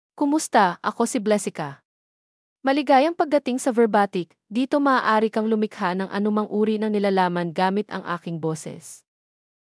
Blessica — Female Filipino (Philippines) AI Voice | TTS, Voice Cloning & Video | Verbatik AI
Blessica is a female AI voice for Filipino (Philippines).
Voice sample
Listen to Blessica's female Filipino voice.
Blessica delivers clear pronunciation with authentic Philippines Filipino intonation, making your content sound professionally produced.